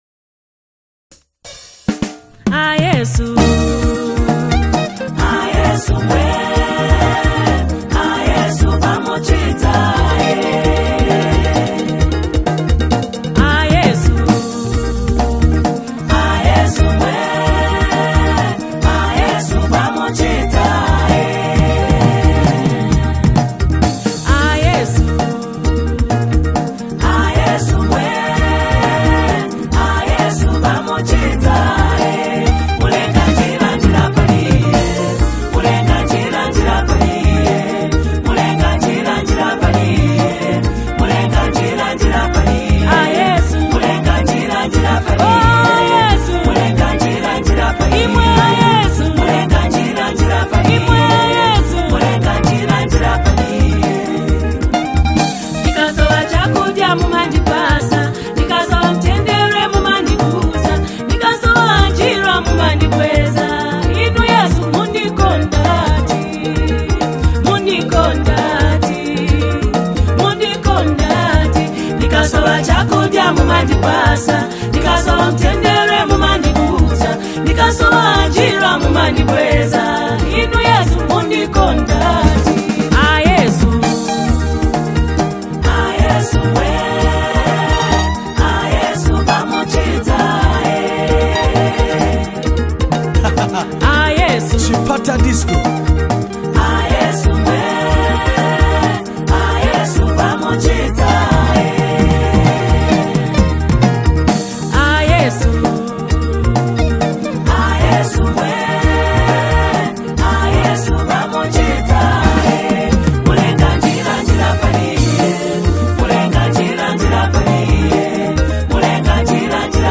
Latest Zambian Rumba Classic Gospel Song